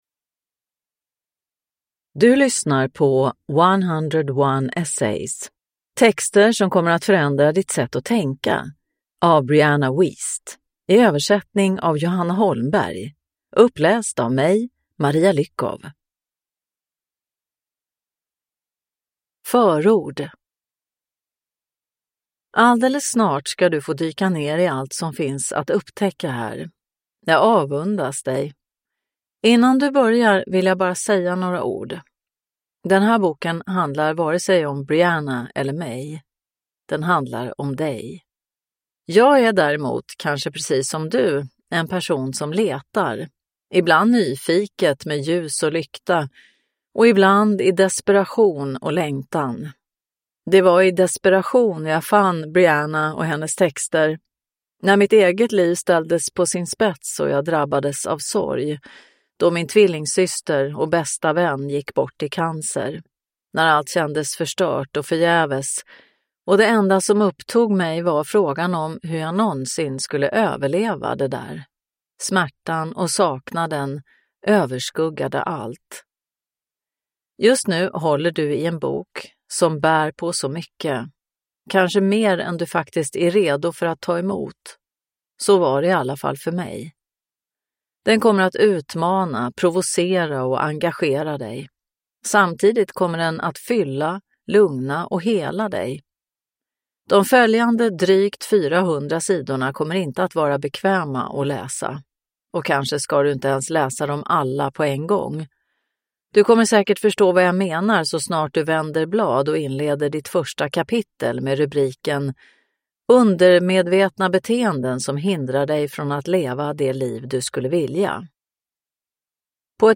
101 Essays: texter som kommer att förändra ditt sätt att tänka – Ljudbok